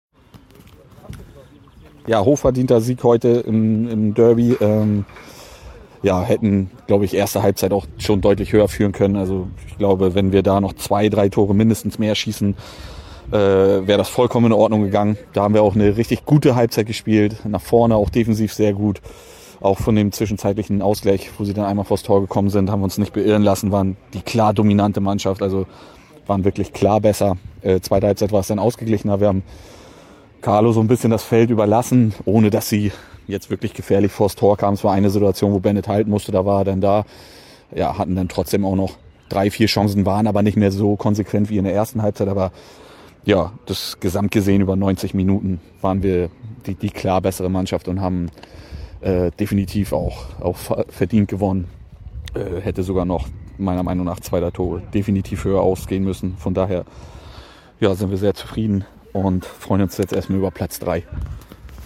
Stimmen zum Spiel